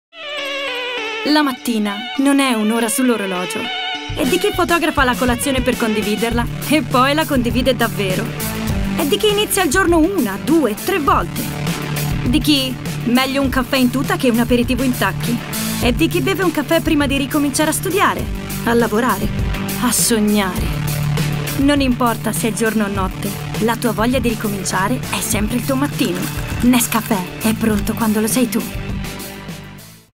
spot tv
caratterizzazioni varie da bambini, ragazzini a donne